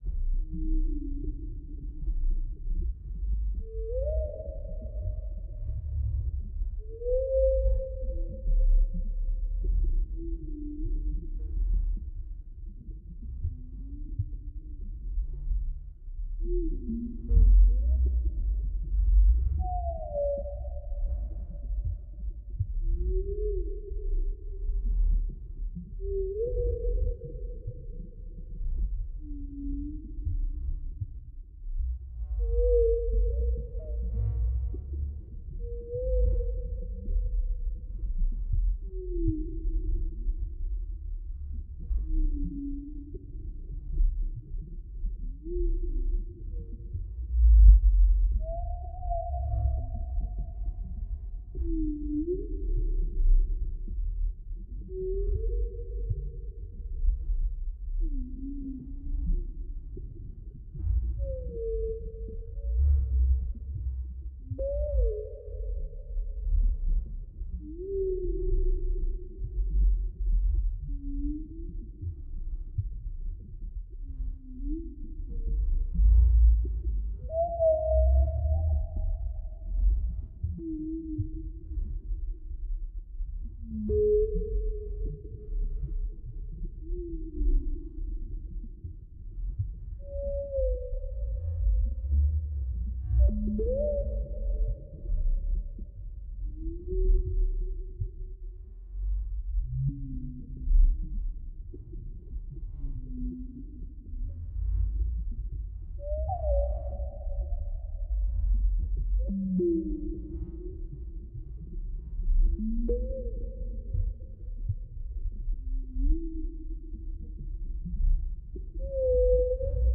Звуки подводного мира
На большой глубине слышно китов